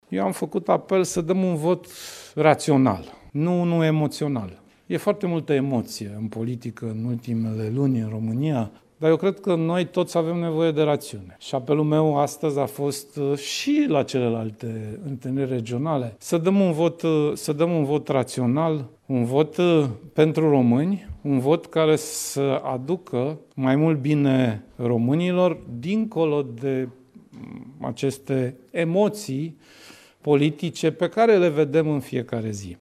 Sorin Grindeanu a avut, vineri, la Sighetu Marmaţiei, o întâlnire cu liderii PSD din regiunea de nord-vest.